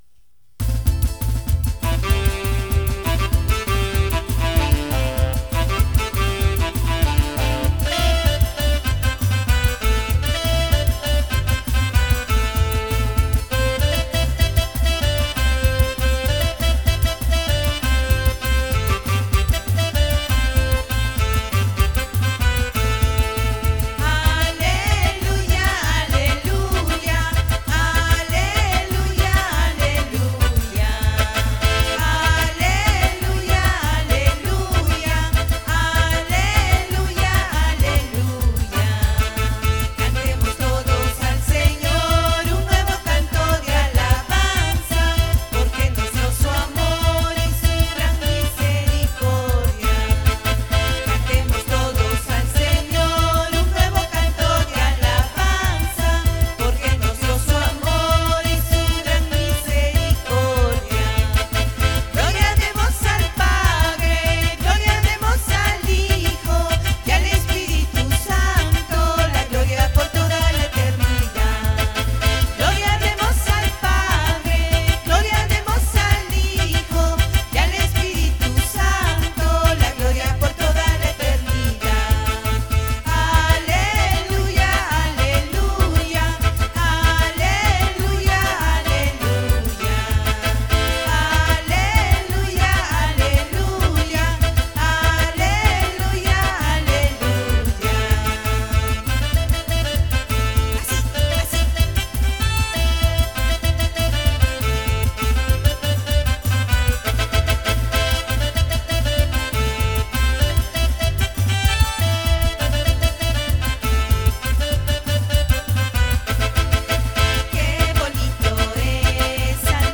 Camp 2016 - Aleluya (Huayno)